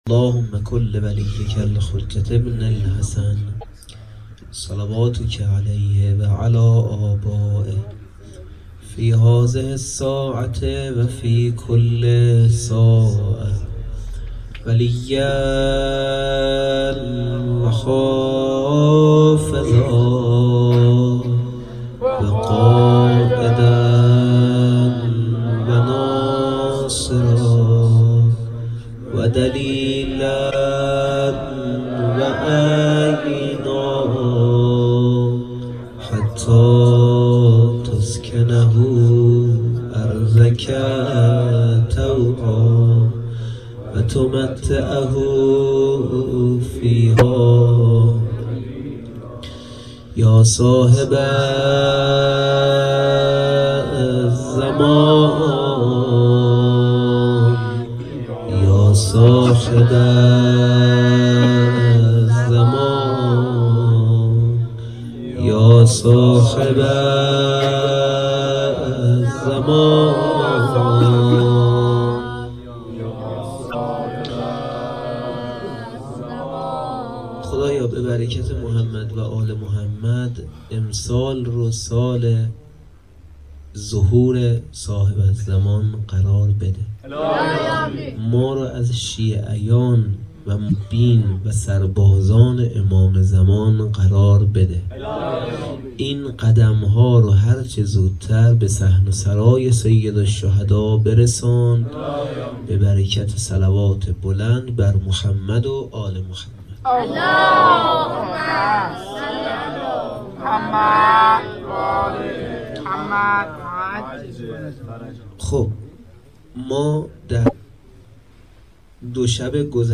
2-sokhanrani.mp3